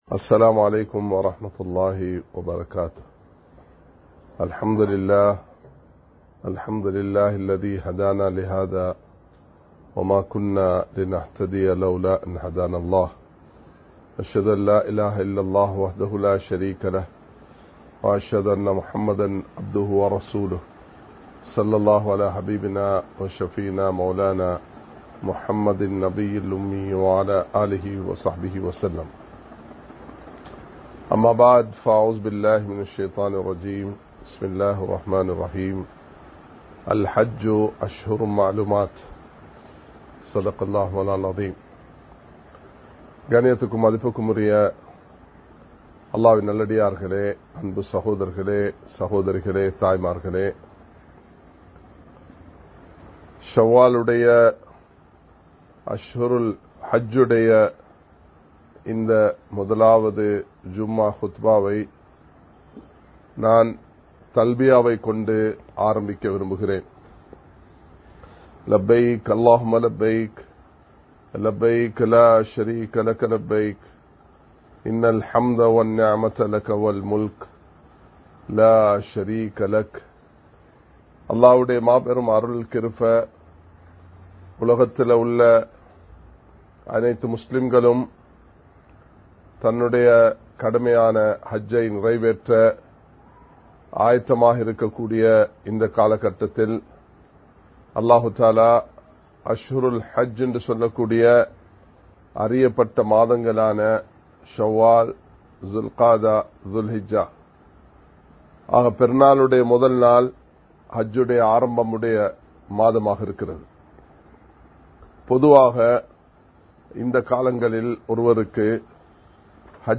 மன்னிப்போம் மறப்போம் (Forgive and Forget) | Audio Bayans | All Ceylon Muslim Youth Community | Addalaichenai
Live Stream